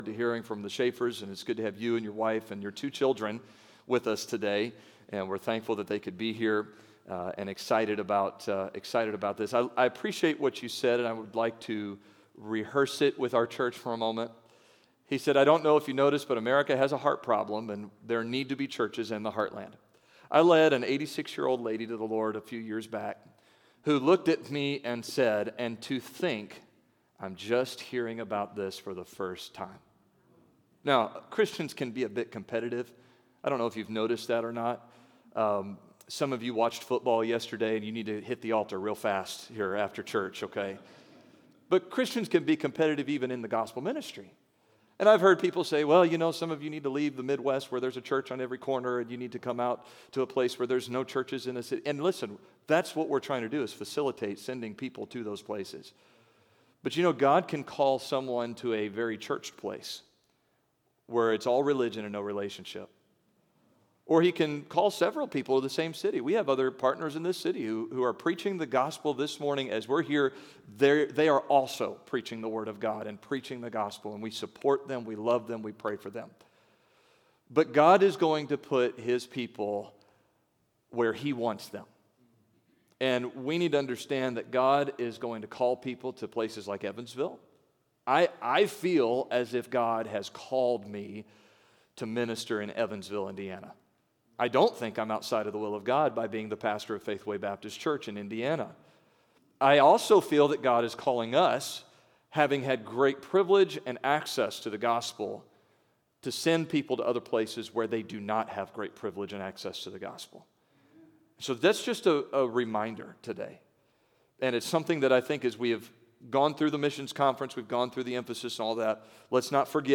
October 2022 Sunday Morning Scripture: Judges 2 Download: Audio Leave A Comment Cancel reply Comment Save my name, email, and website in this browser for the next time I comment.